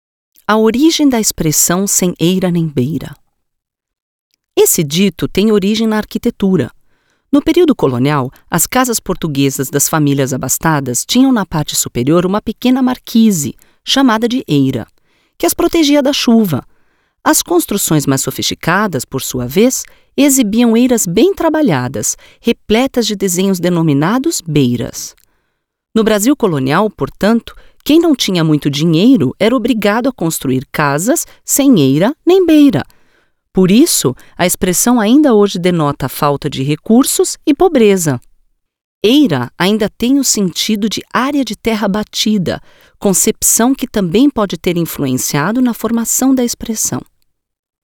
Kräftige, ausdrucksvolle Stimme.
Sprechprobe: Werbung (Muttersprache):
Strong, expressive voice.